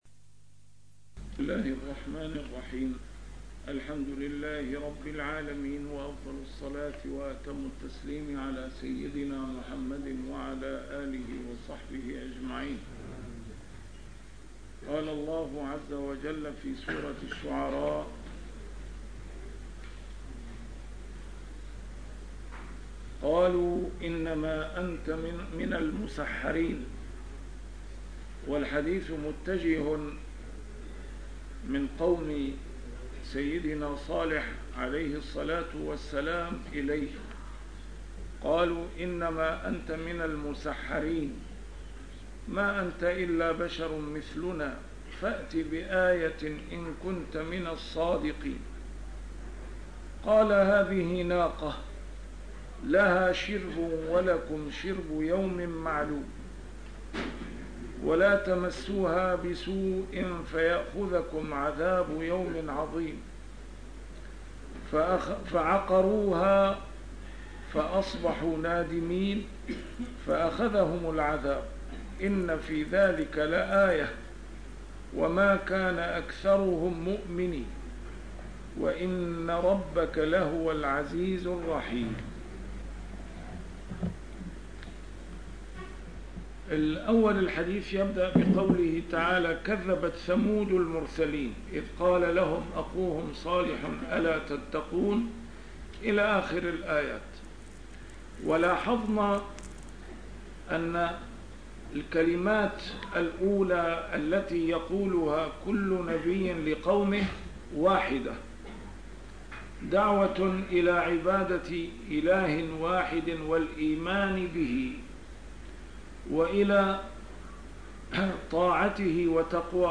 A MARTYR SCHOLAR: IMAM MUHAMMAD SAEED RAMADAN AL-BOUTI - الدروس العلمية - تفسير القرآن الكريم - تسجيل قديم - الدرس 234: الشعراء 153-159